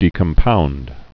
(dēkəm-pound)